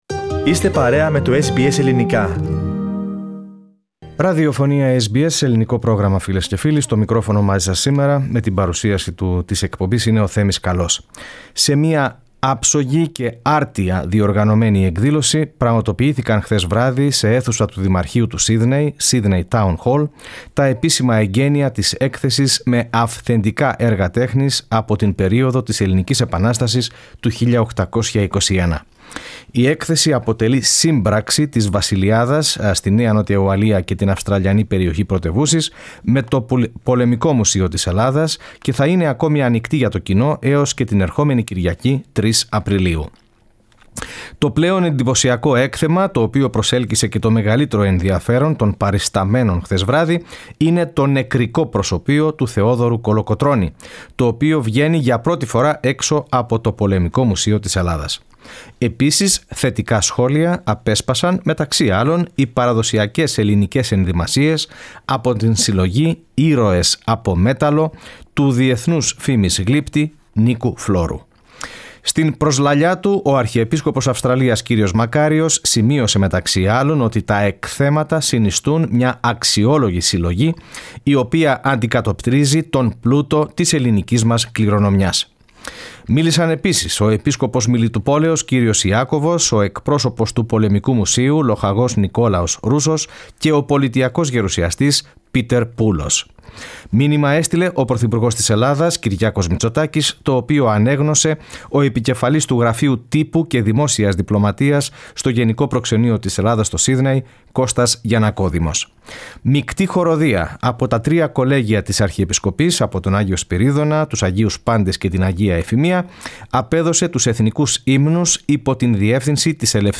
Σε μια άρτια διοργανωμένη εκδήλωση πραγματοποιήθηκαν χθες βράδυ σε αίθουσα του Δημαρχείου του Σύδνεϋ, Sydney Town Hall, τα επίσημα εγκαίνεια της έκθεσης με αυθεντικά έργα τέχνης από την Ελληνική Επανάσταση του 1821.
Η μαθητική χορωδία που απέδωσε τους εθνικούς ύμνους Source: SBS Greek